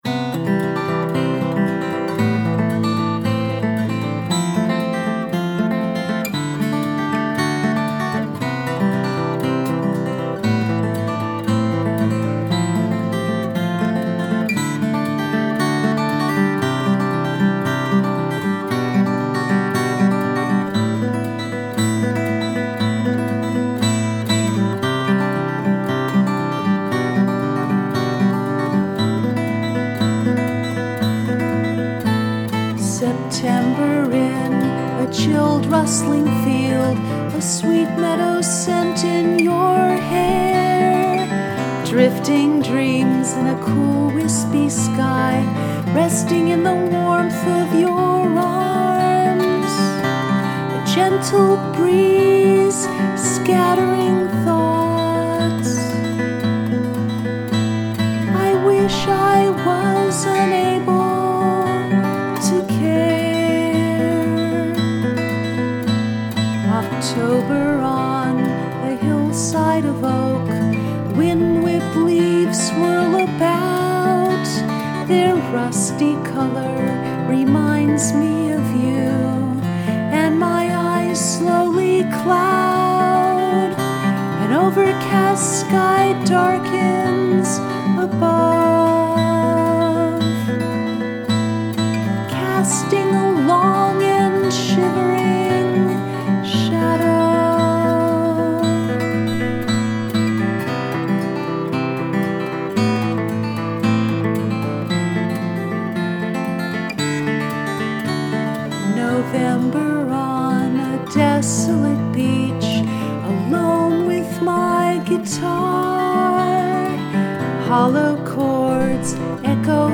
Autumn Recollections – Solo Piano Melody